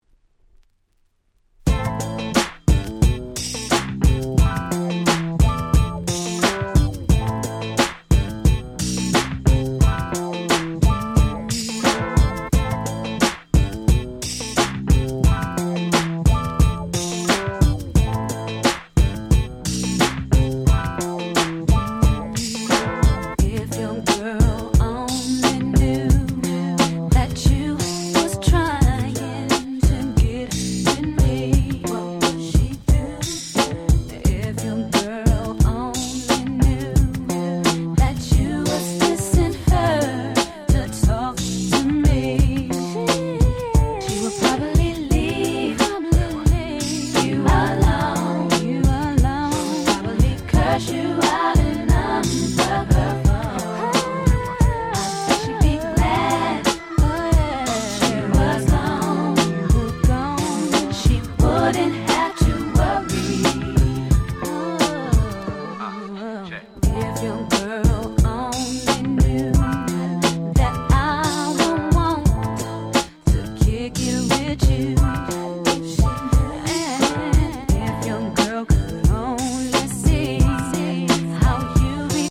DJに使い易い様にIntro付きに改良した